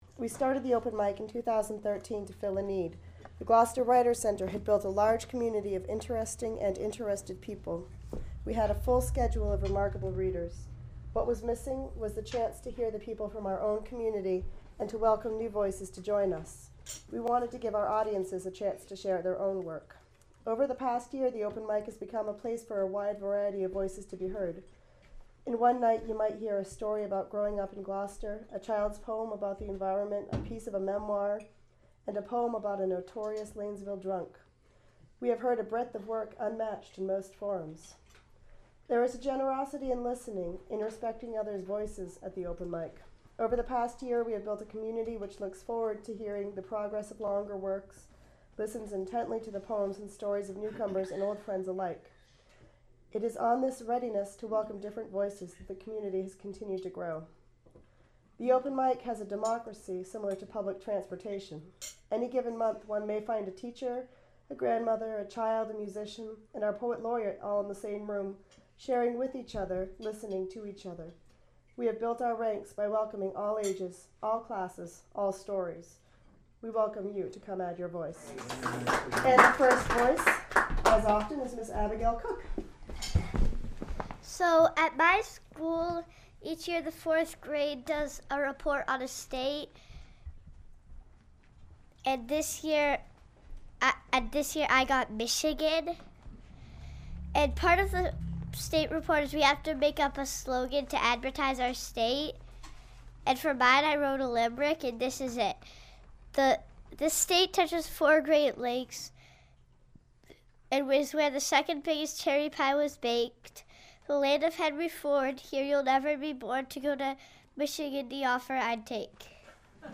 Open Mic Night – 3/03/14